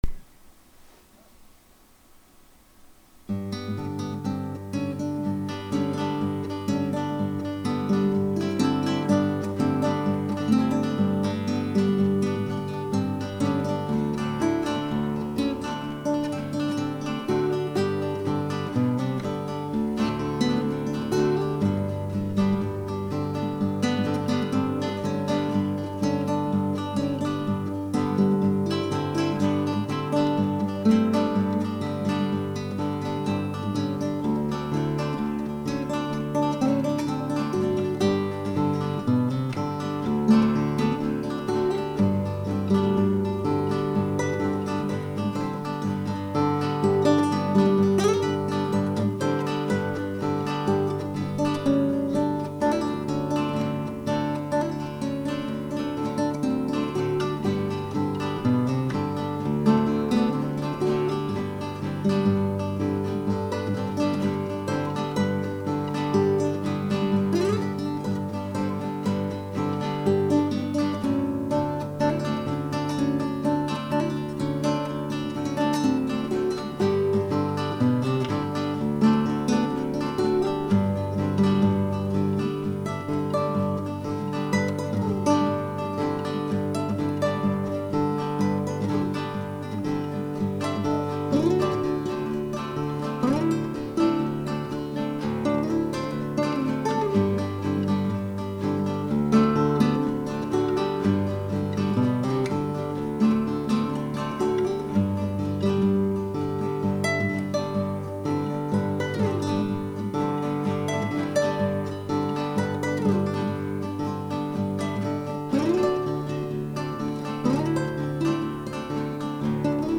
for performing and teaching the art Hawaiian Slack Key at our July 9th meeting.